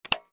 switch-off.mp3